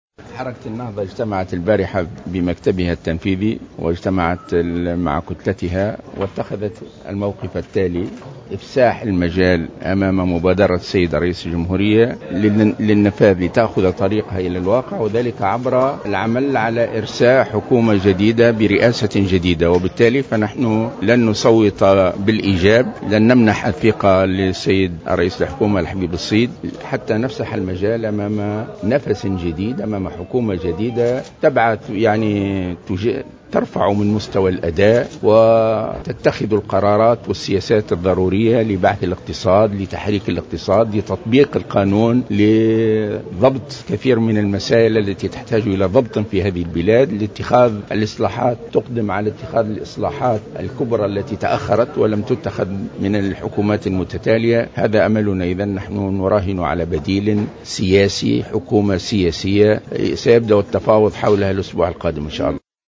وأضاف الغنوشي في تصريح لمراسل "الجوهرة أف أم" أن النهضة لن تصوت لفائدة حكومة الحبيب الصيد حتى تفتح المجال أمام حكومة جديدة برئاسة جديدة، ترفع من مستوى الاداء وتتخذ القرارات والسياسية الضرورية لتحريك الاقتصاد، وفق تعبيره.